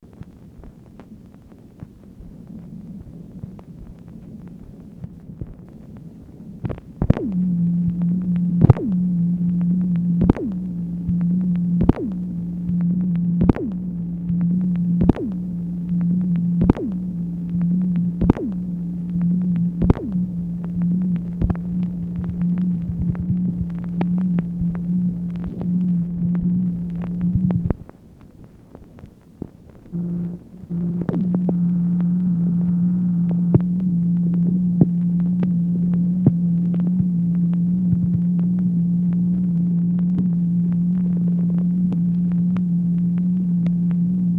MACHINE NOISE, May 19, 1965
Secret White House Tapes | Lyndon B. Johnson Presidency